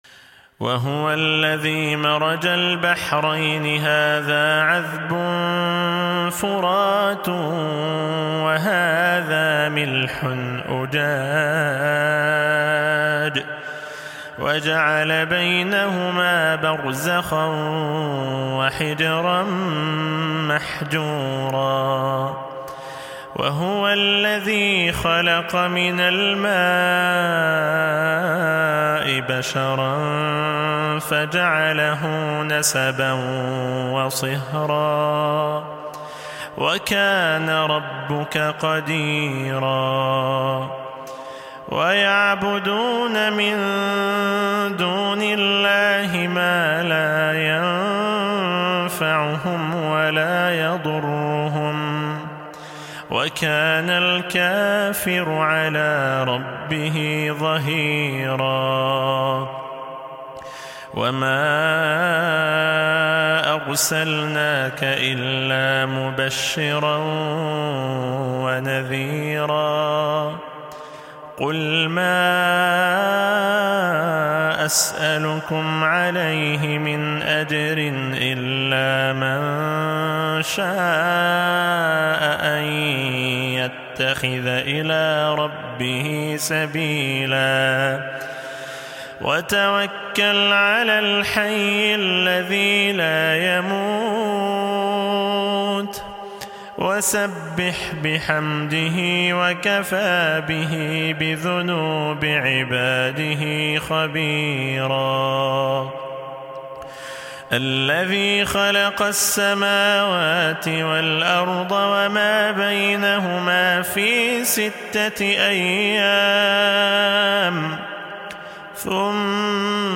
من سورة الفرقان من صلاة الفجر بصوت اخيكم